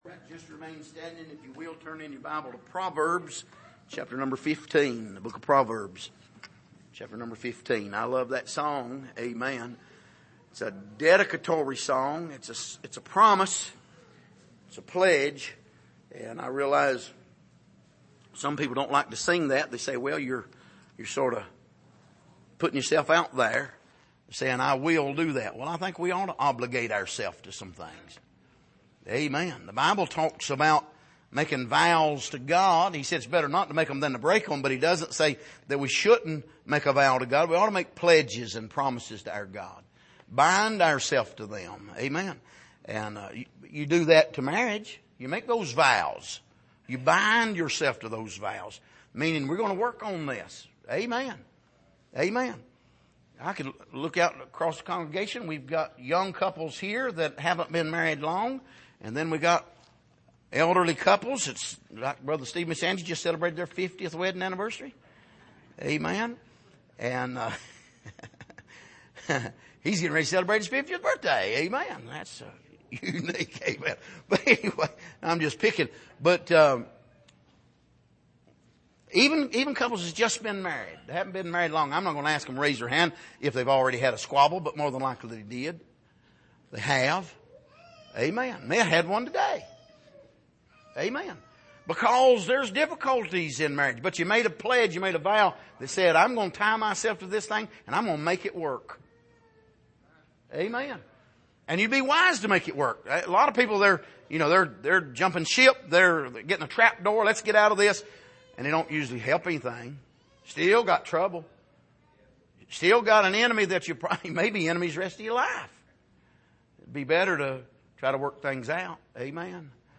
Passage: Proverbs 15:1-5,7,14,23,26,28 Service: Sunday Evening